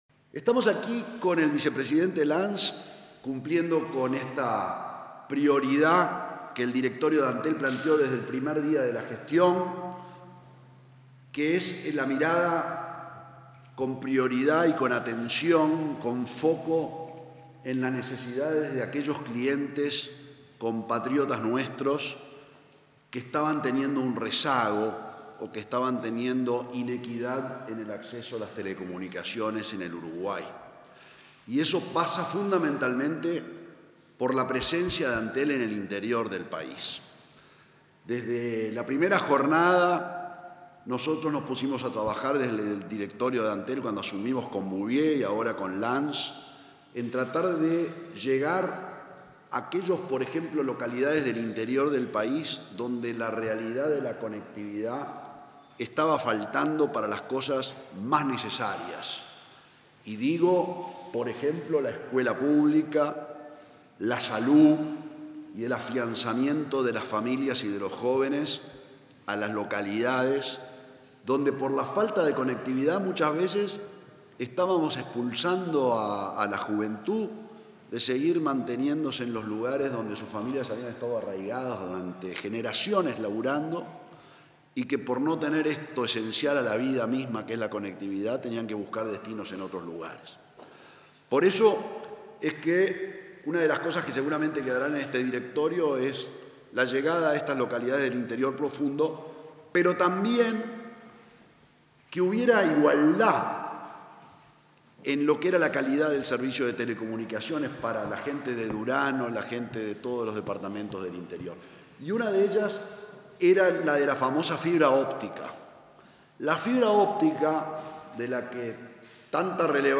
Palabras del presidente de Antel, Gabriel Gurméndez
Palabras del presidente de Antel, Gabriel Gurméndez 05/06/2023 Compartir Facebook X Copiar enlace WhatsApp LinkedIn En el marco de las actividades que desarrolla Antel en el interior del país, este 5 de junio, el presidente de la empresa, Gabriel Gurméndez, visitó el departamento de Durazno.